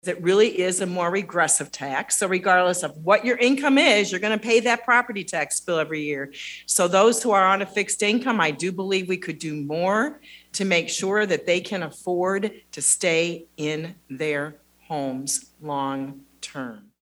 The legislators made their comments during a forum sponsored by the Iowa Taxpayers Association.